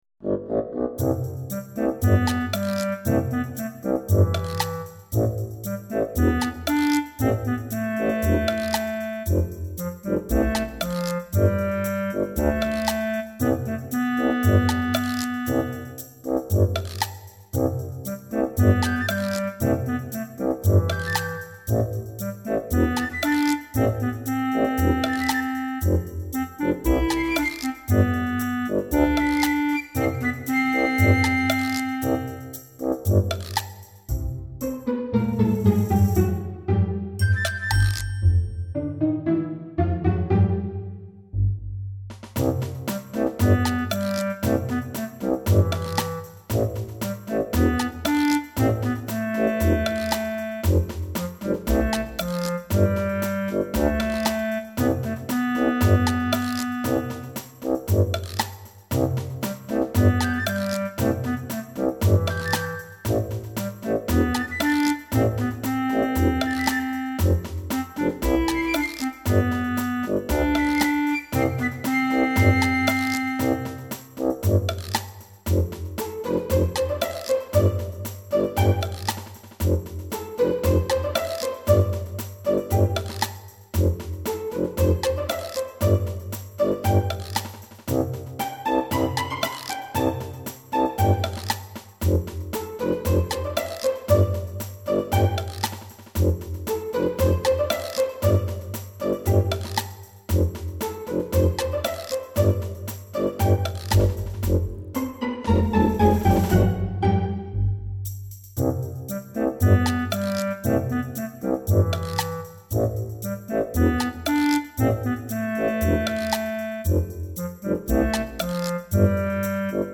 クラリネットとフルートをメインに、少々間の抜けた雰囲気の楽曲。 明るく可愛げな雰囲気。 ゲーム用BGMとして作成。